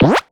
collect_item_20.wav